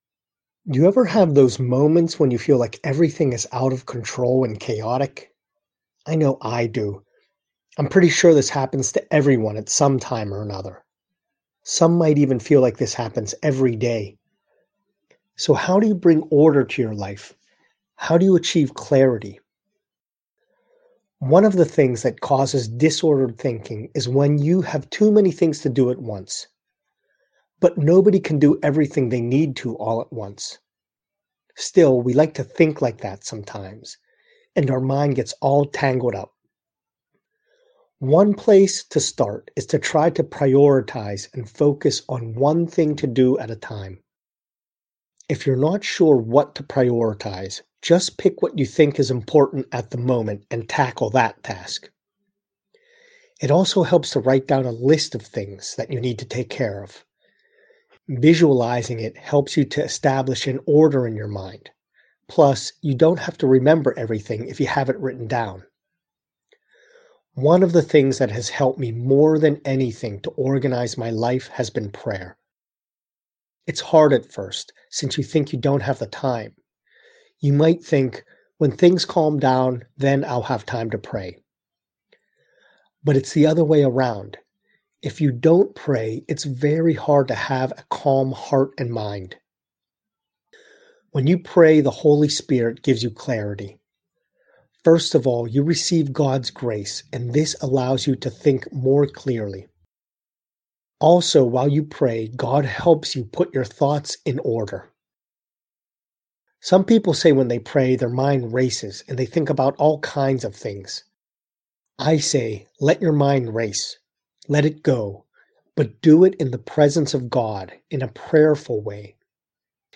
Audio message and prayer